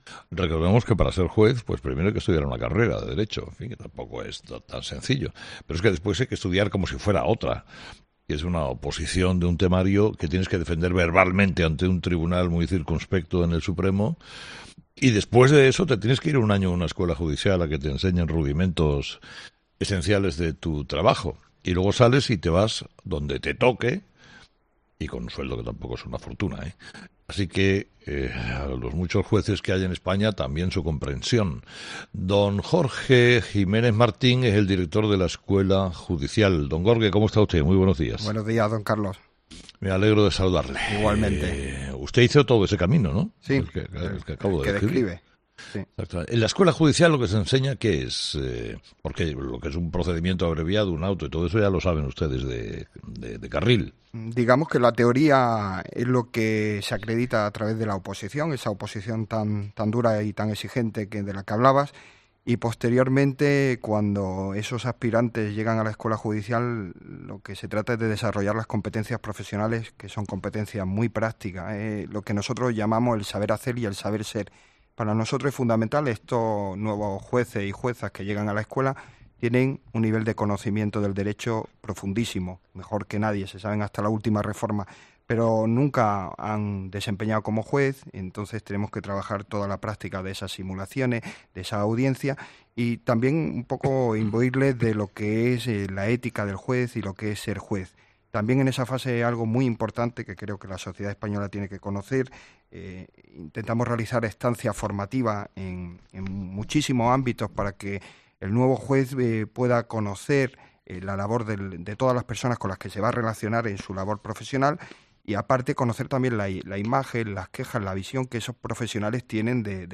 El director de la Escuela Judicial, Jorge Jiménez Martín, denuncia el acoso y los atentados contra sedes judiciales catalanas
En una entrevista en 'Herrera en COPE', Jiménez Martín ha detallado que el acoso lo recibe sobre todo “el juez que lleva casos que pueden atentar contra la causa independentista”.